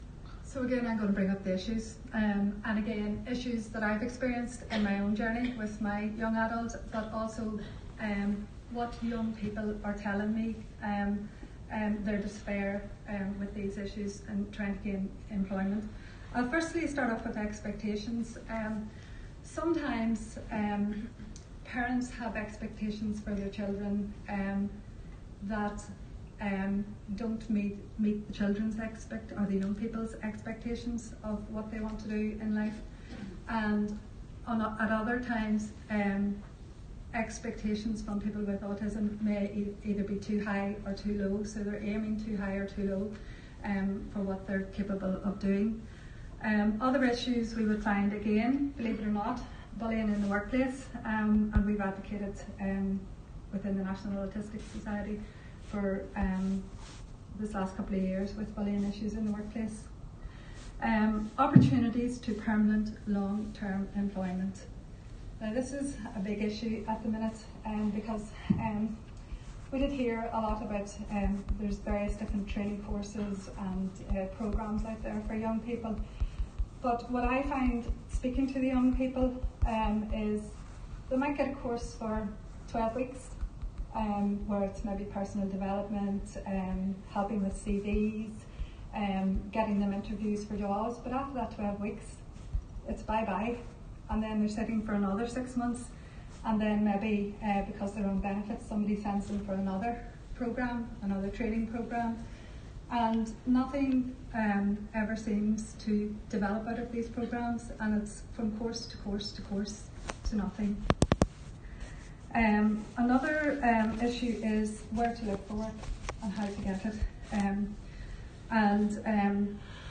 talk about issues affecting adults with autism at the Belfast Health and Social Care Trust Adult Autism Family workshop